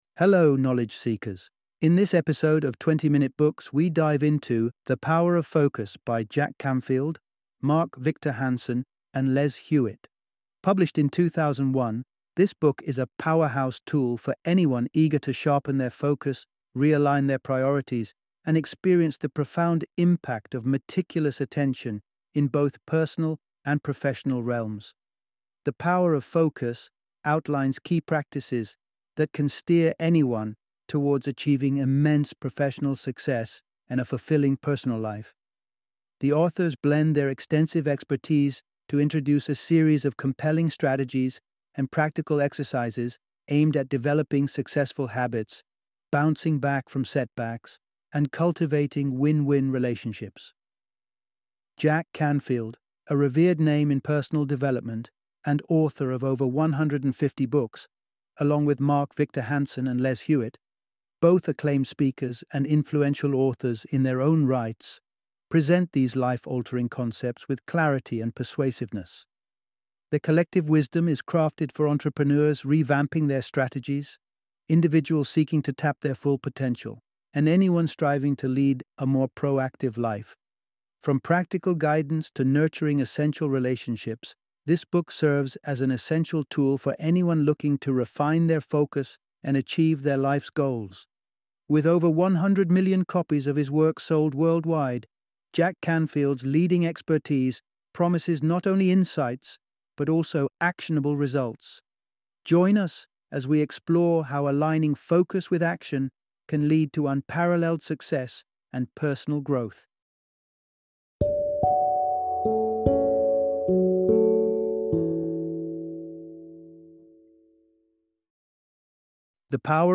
The Power of Focus - Audiobook Summary